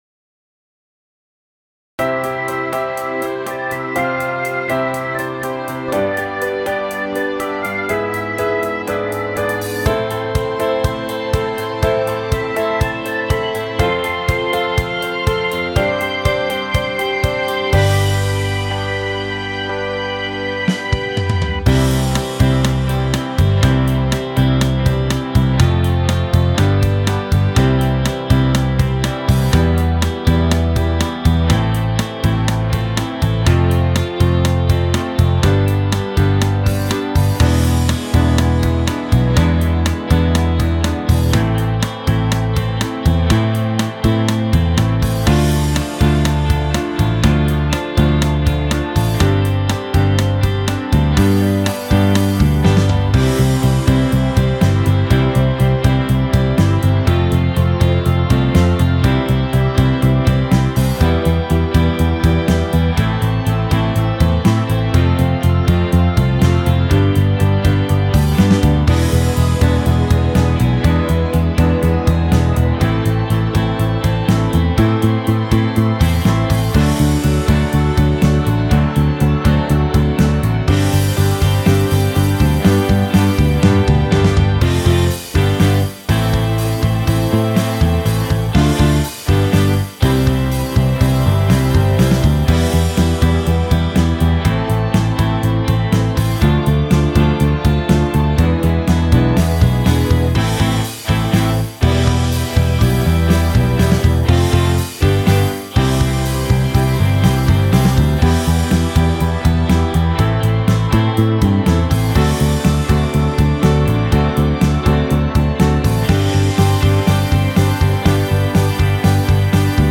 ボーカルなし楽曲（カラオケ）